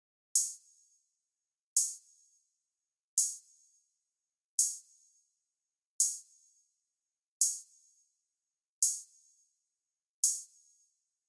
朴素的开放性帽子1
Tag: 170 bpm Rap Loops Drum Loops 1.90 MB wav Key : D